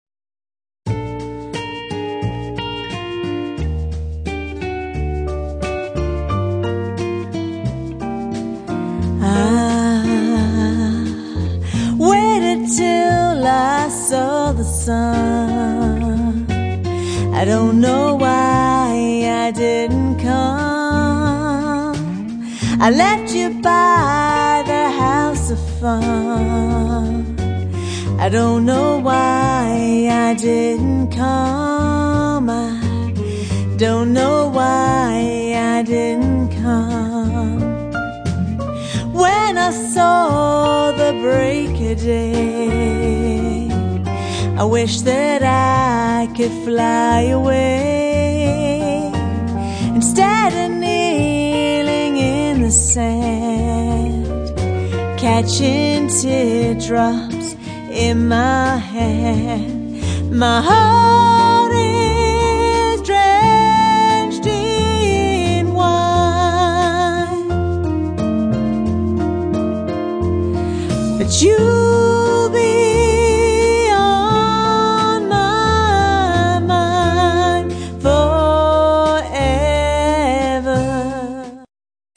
• Six-piece band
• Two female lead vocalists